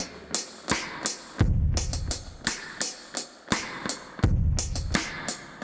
Index of /musicradar/dub-designer-samples/85bpm/Beats
DD_BeatD_85-01.wav